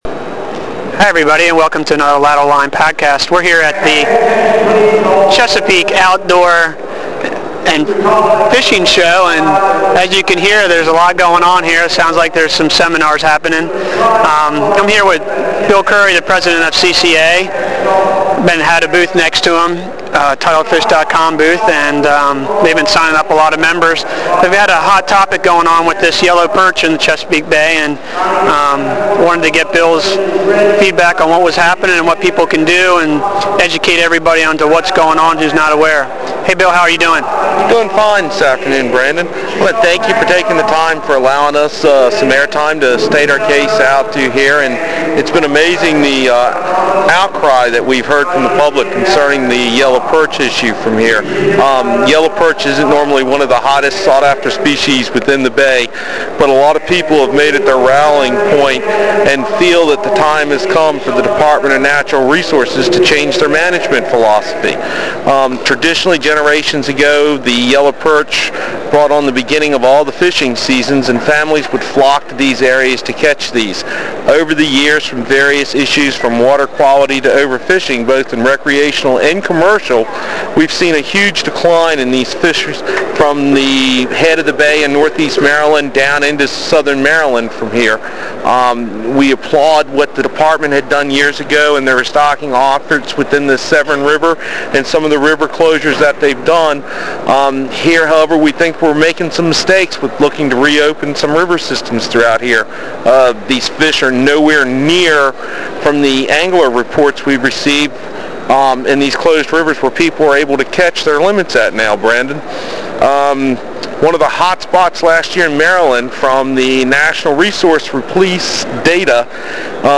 I thought it would be cool to start a fishing podcast radio show to share my conversations with other anglers and do some interviews with charters, guides, fisheries scientists, fisheries managers, and other fishy people.
FishingCast #5 - Interview